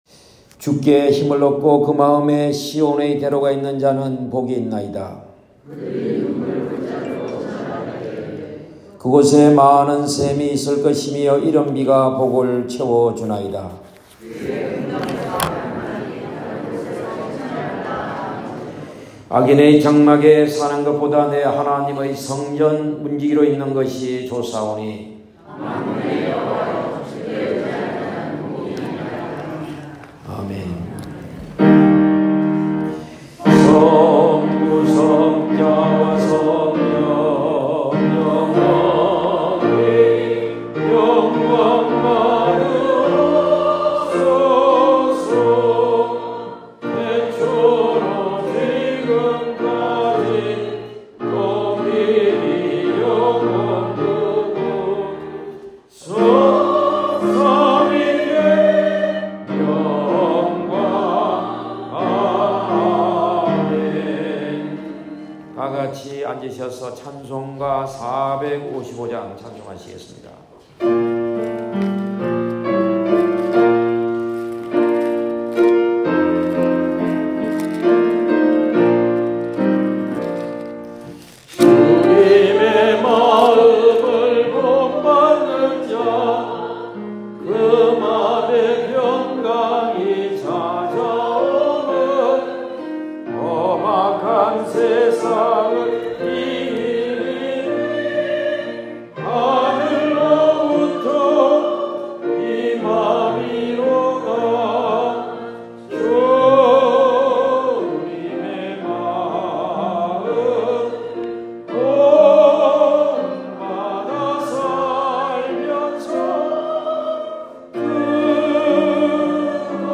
2023년 4월 23일 주일예배(2부 오전 11시) 기도, 찬양대